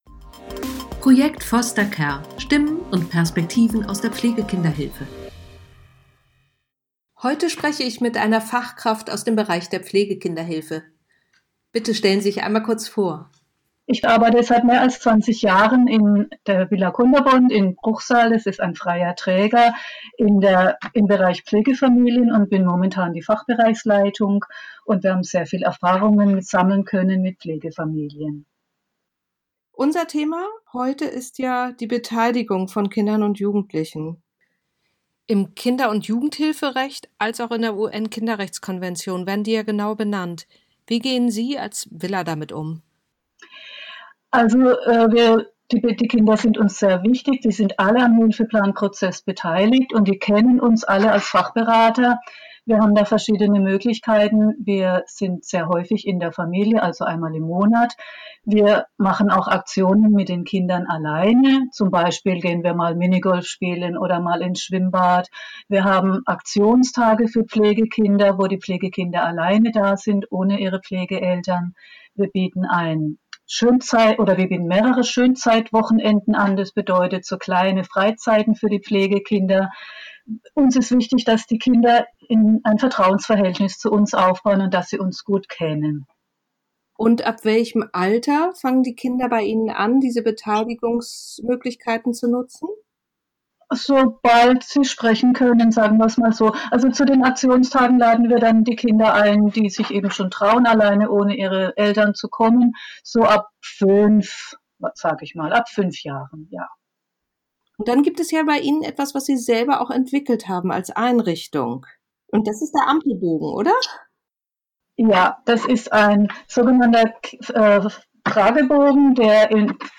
Die Beteiligung von jungen Menschen kann durch verschiedene Instrumente, je nach Alter der Kinder und Jugendlichen unterstützt und gefördert werden. Eine Fachkraft einer*s freien Träger*in der Pflegekinderhilfe stellt verschiedene Beteiligungsmöglichkeiten, wie beispielweise einen „Ampelbogen“ vor, der genutzt wird um herauszufinden wie es den Kindern und Jugendlichen geht, welche Wünsche und Bedürfnisse sie haben.